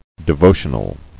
[de·vo·tion·al]
De*vo"tion*al*ly , adv.